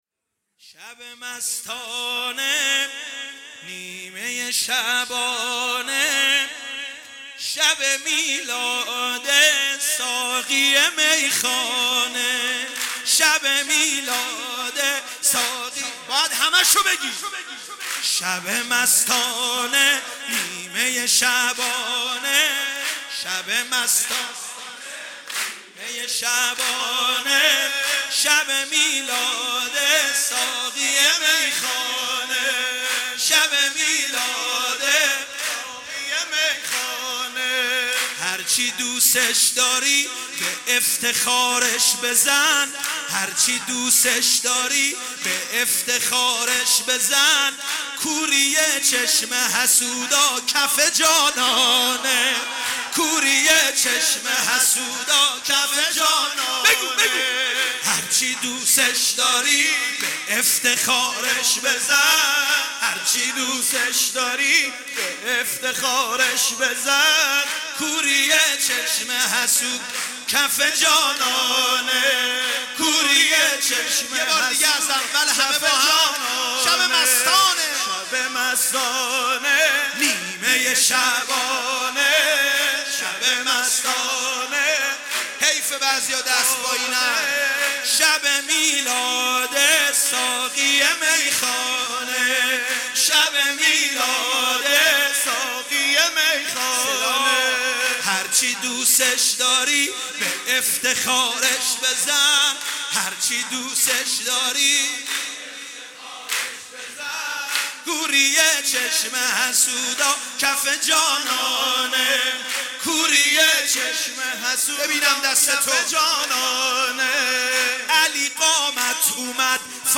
مولودی